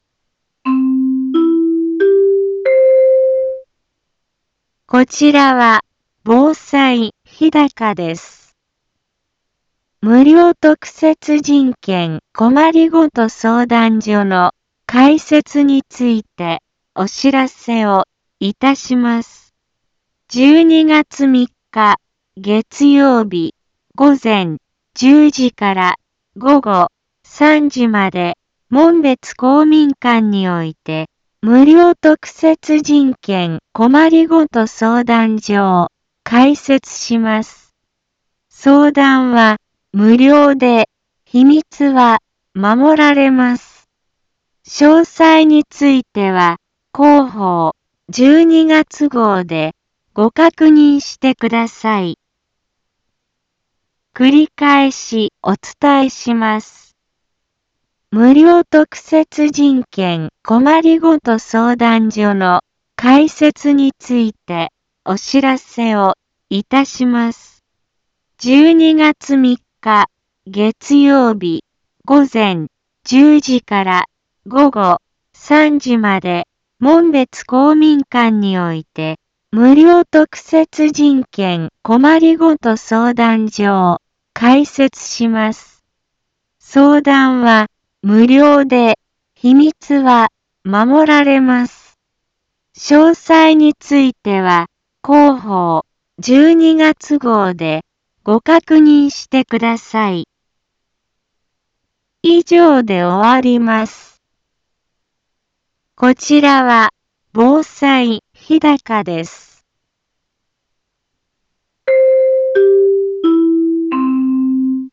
一般放送情報
Back Home 一般放送情報 音声放送 再生 一般放送情報 登録日時：2018-11-27 10:04:11 タイトル：無料特設人権・困りごと相談所開設のお知らせ インフォメーション：こちらは、防災日高です。